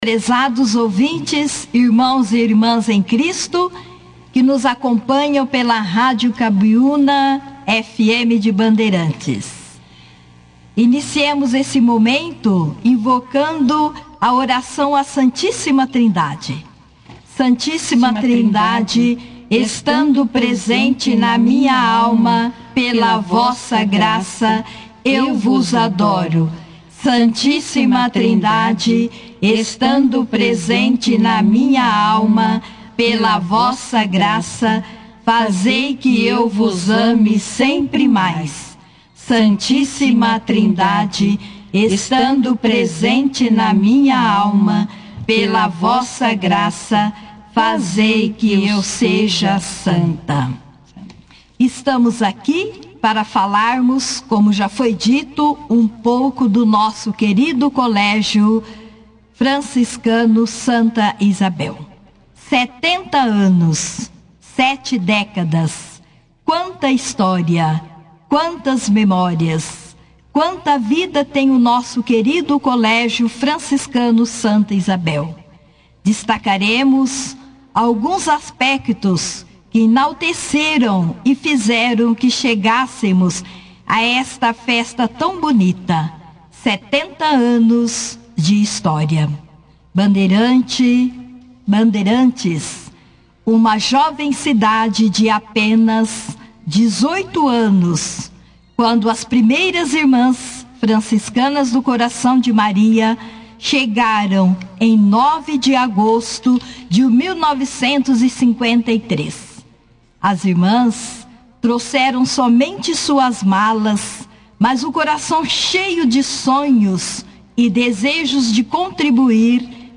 Durante a entrevista, elas compartilharam detalhes dos diversos ciclos pelos quais o colégio passou, enfatizando a evolução constante que o estabelecimento tem vivenciado ao longo dos anos.